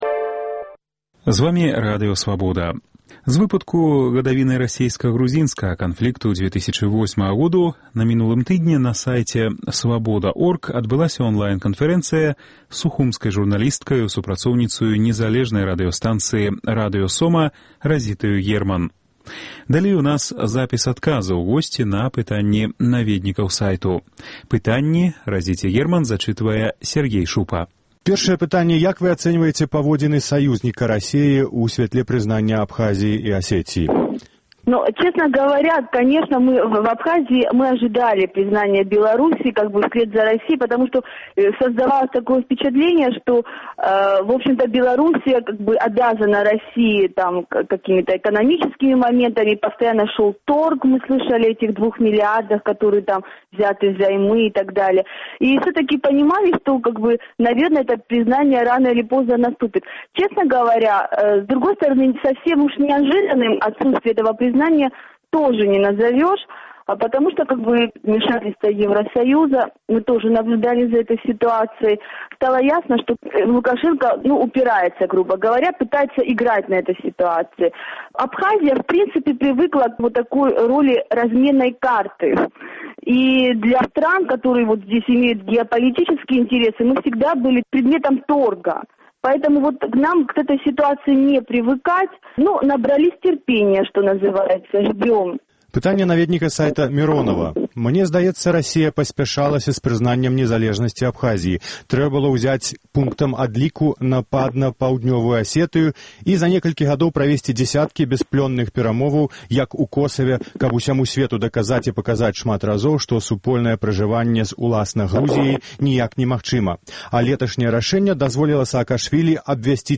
Онлайн- канфэрэнцыя